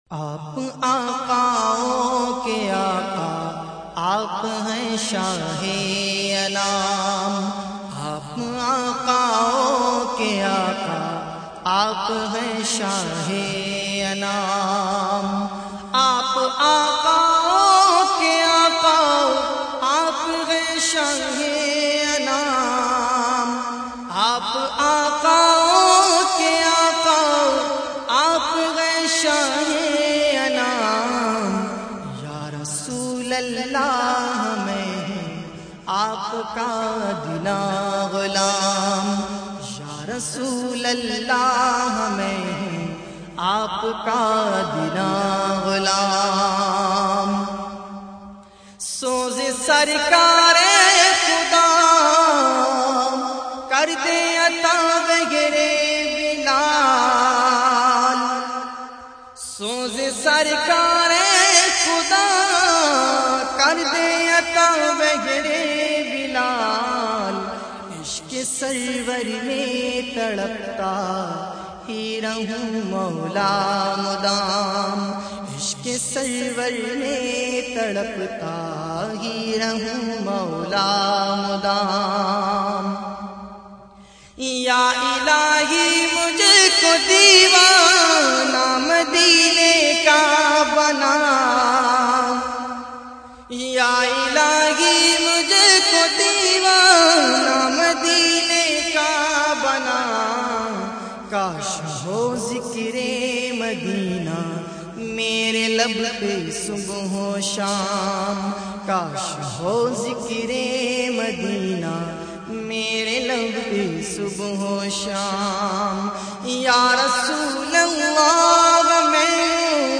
urdu naats